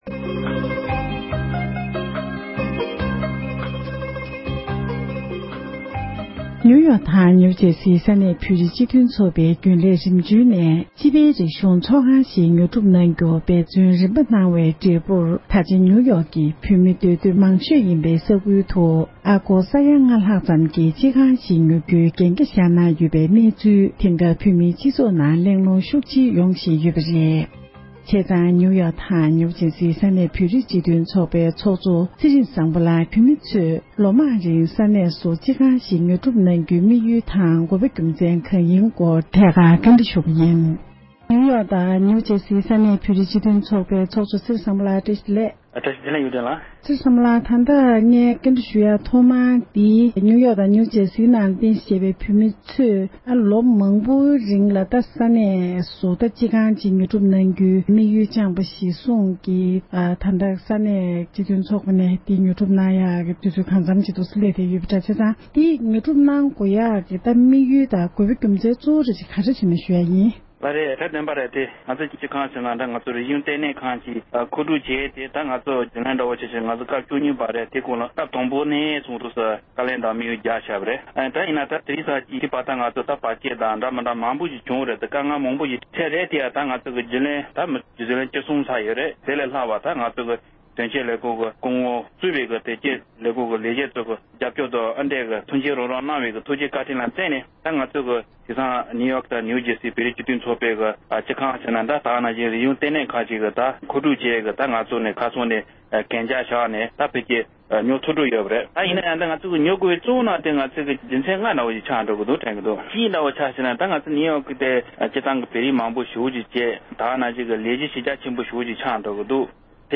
འབྲེལ་ཡོད་མི་སྣར་གནས་འདྲི་ཞུས་ཏེ་གནས་ཚུལ་ཕྱོགས་སྒྲིག་ཞུས་པ་ཞིག་གསན་རོགས་ཞུ༎